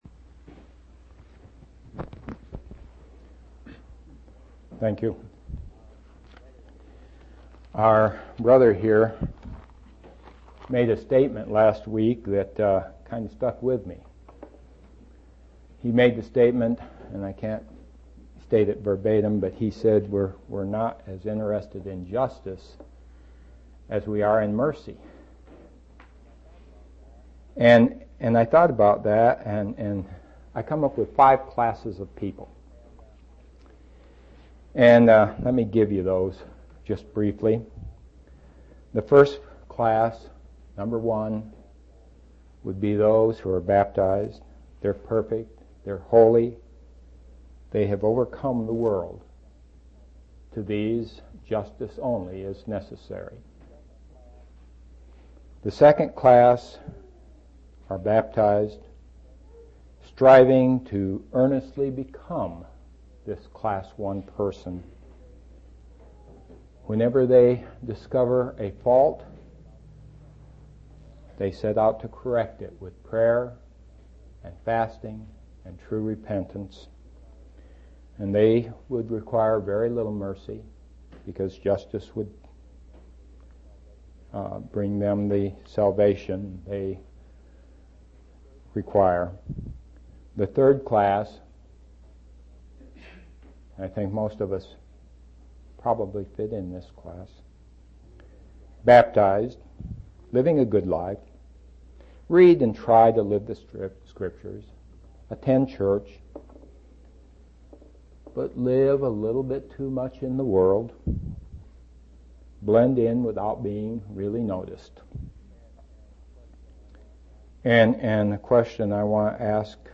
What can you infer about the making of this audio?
11/12/1995 Location: East Independence Local Event